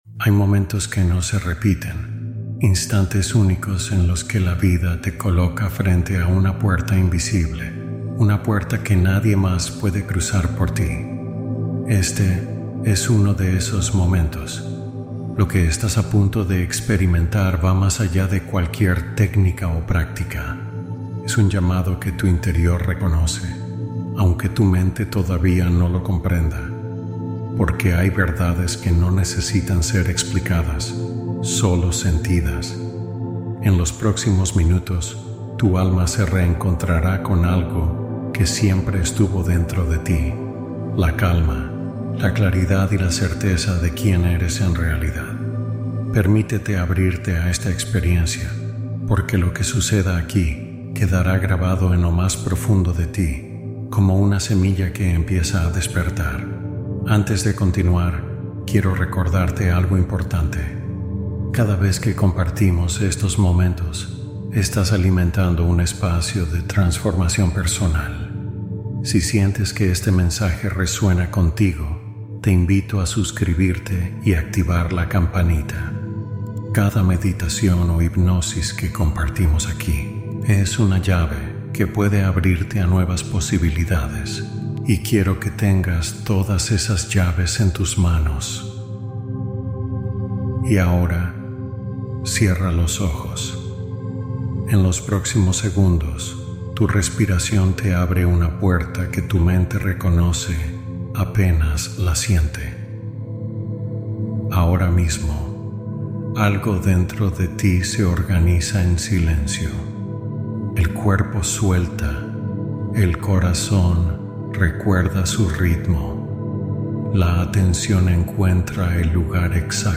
Escucha y Recibe Respuestas de Tu Yo Superior | Meditación Guiada